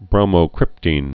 (brōmō-krĭptēn)